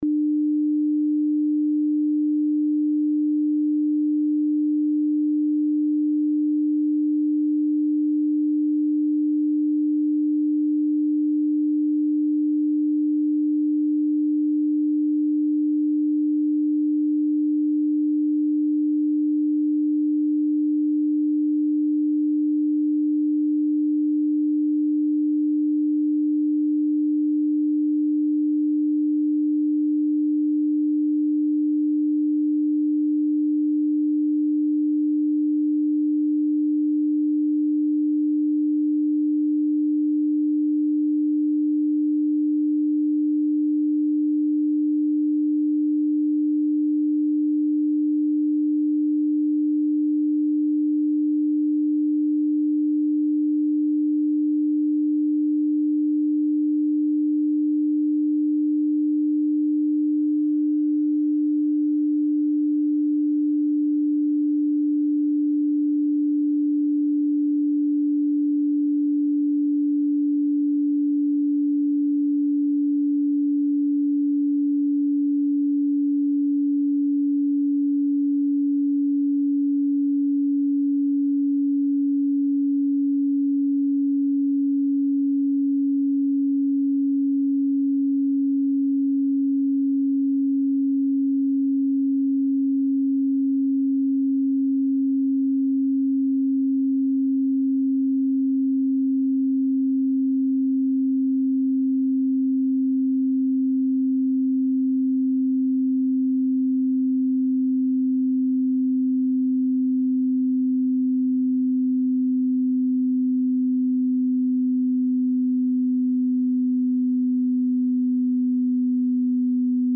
Left Descending - sample - this is the first 5 minutes of the left channel of Beach Meds and can be used with Right Descending and your choice of other ambient sounds.